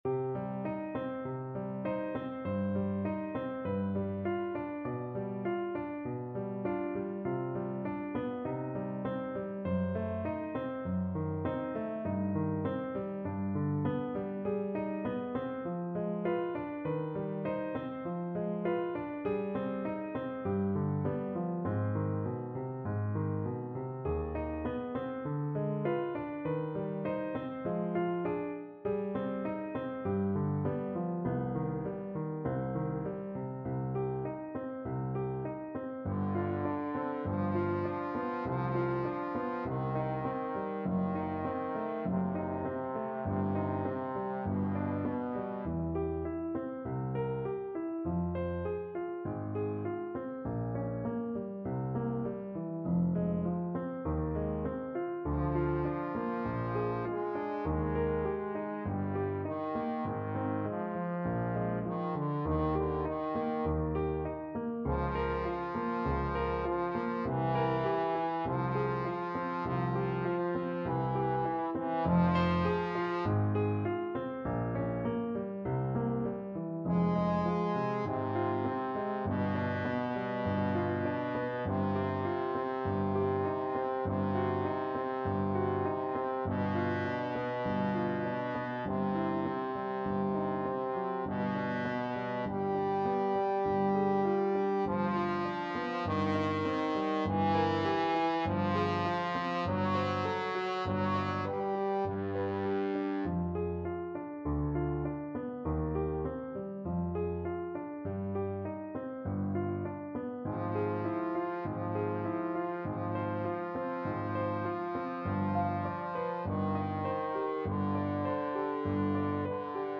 Free Sheet music for Trombone Duet
C minor (Sounding Pitch) (View more C minor Music for Trombone Duet )
Largo assai = c.50
4/4 (View more 4/4 Music)
Classical (View more Classical Trombone Duet Music)